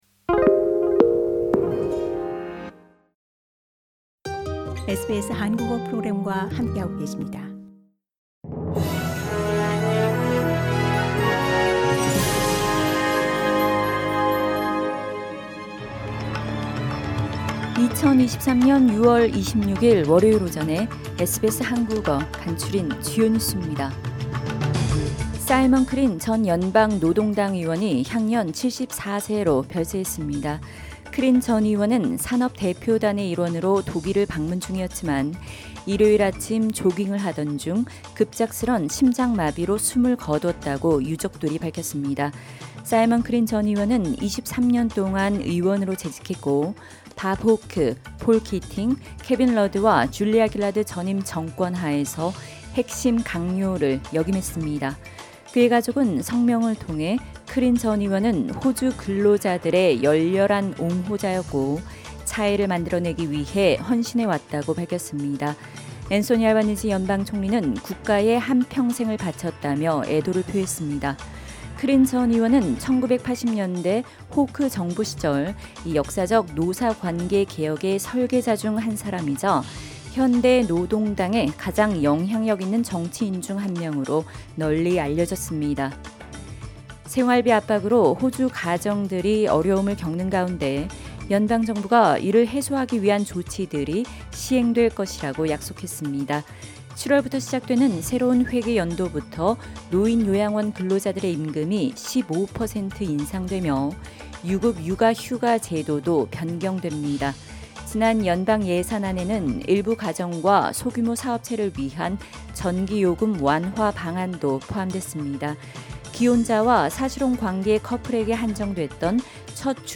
2023년 6월 26일 월요일 아침 SBS 한국어 간추린 주요 뉴스입니다.